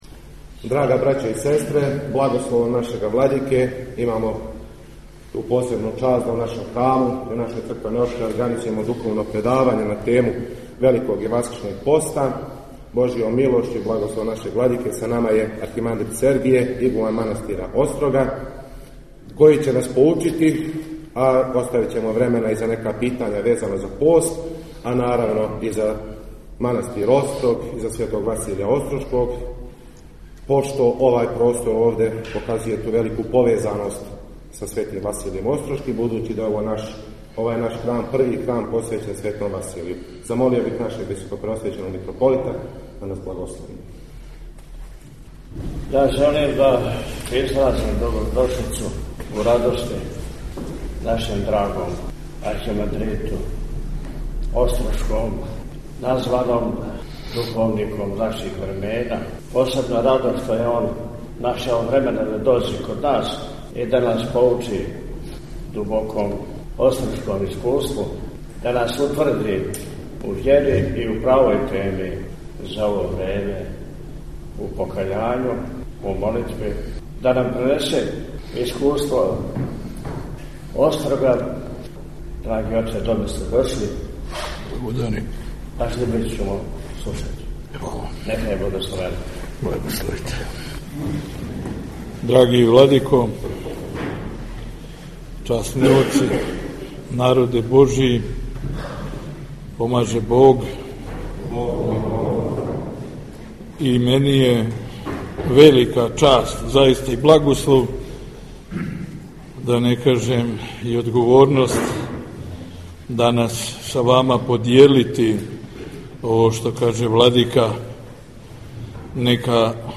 У Саборном храму у Пријепољу одржано духовно предавање - Eпархија Милешевска